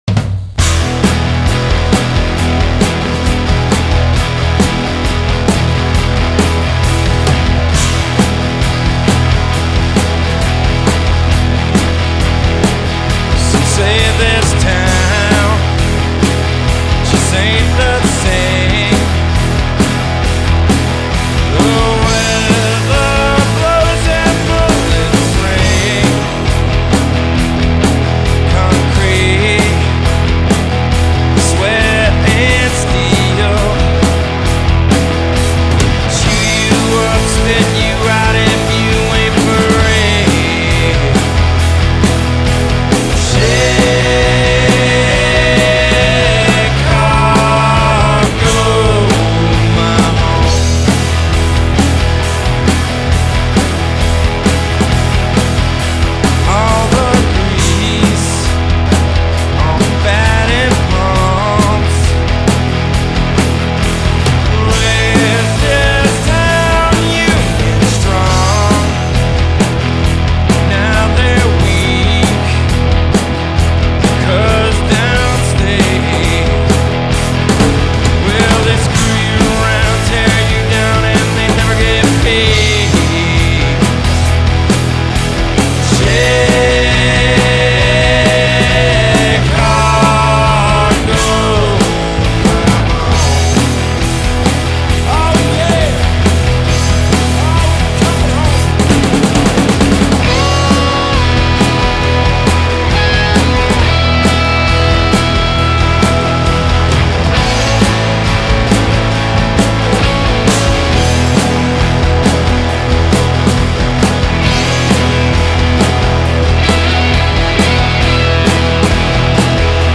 Drums
Bass
Piano & Organ
Guitar & Vocals